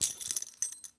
foley_keys_belt_metal_jingle_02.wav